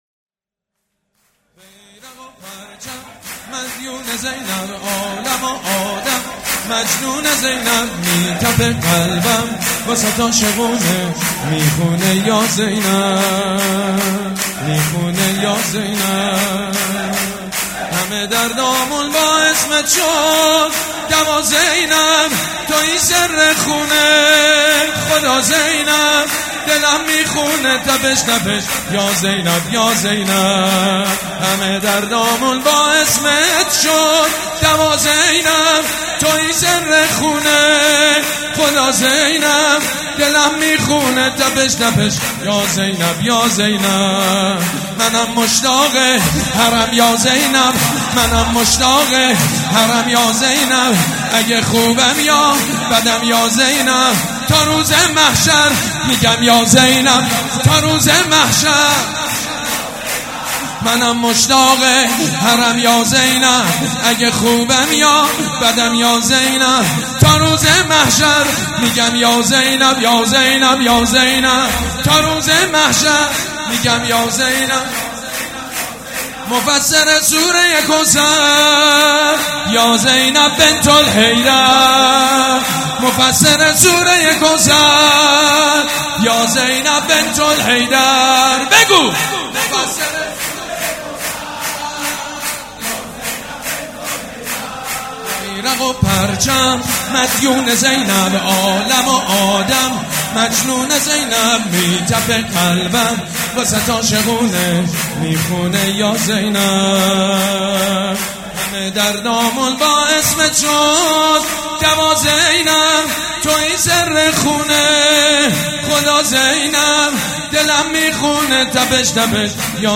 شور
مداح
حاج سید مجید بنی فاطمه
ولادت حضرت زینب (س)